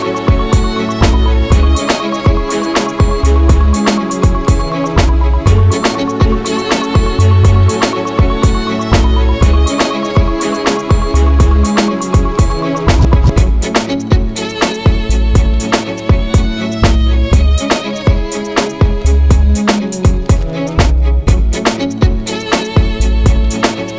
no Backing Vocals R'n'B / Hip Hop 4:17 Buy £1.50